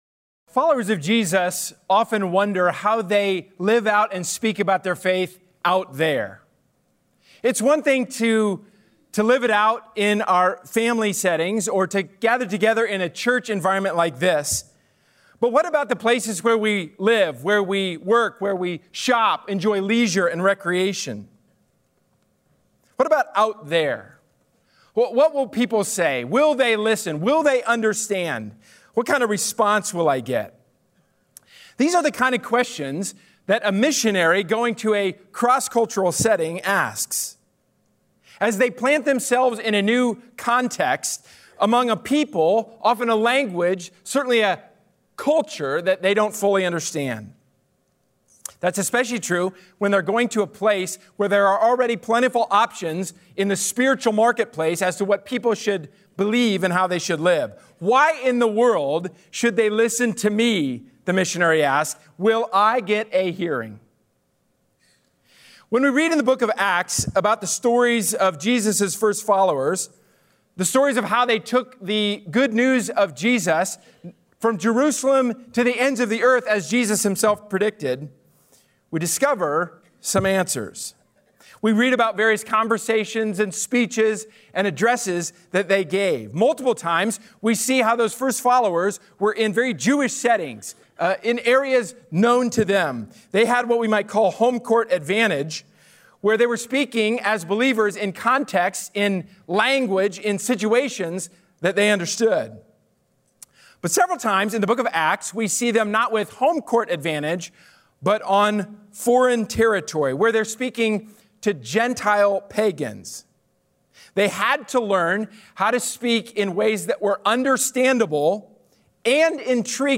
A sermon from the series "Can I Get a Witness."